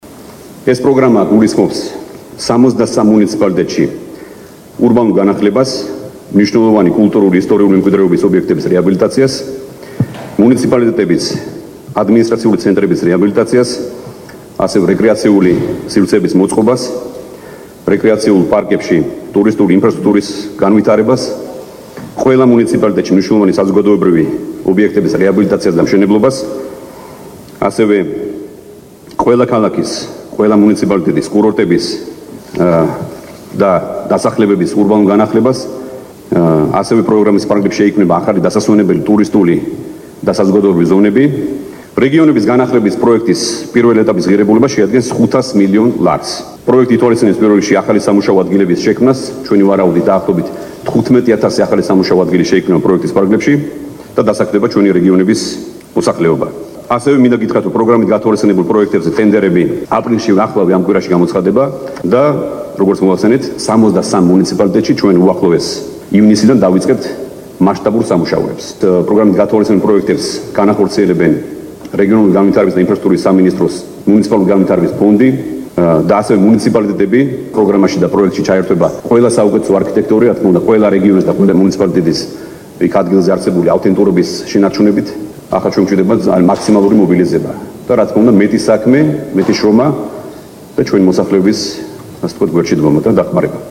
მოისმინეთ, ირაკლი ღარიბაშვილის ხმა